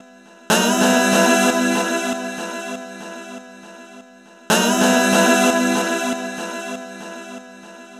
Astro 4 Vox Wet-E.wav